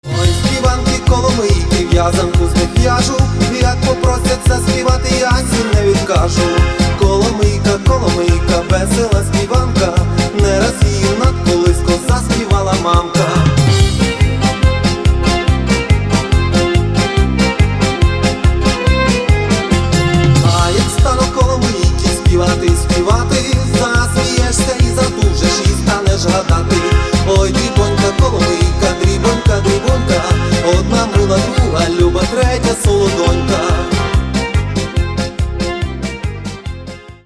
Каталог -> MP3-CD -> Народная